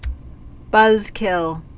(bŭzkĭl)